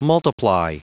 Prononciation du mot multiply en anglais (fichier audio)
Prononciation du mot : multiply